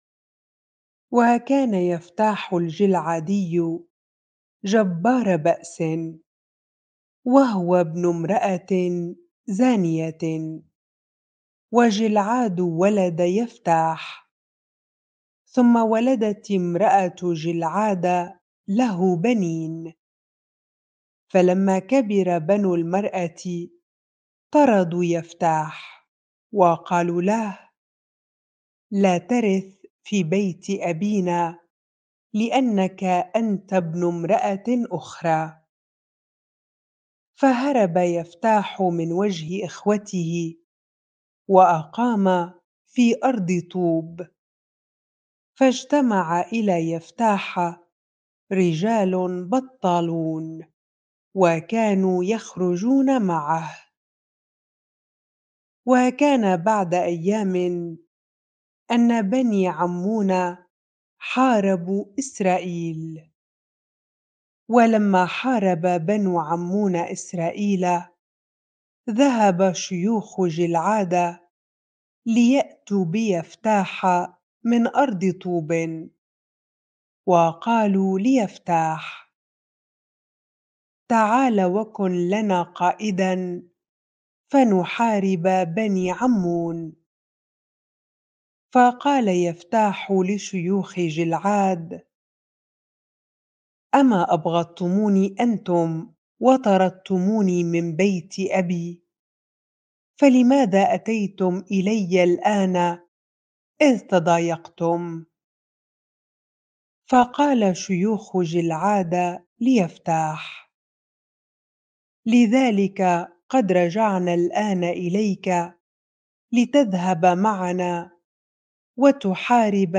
bible-reading-Judges 11 ar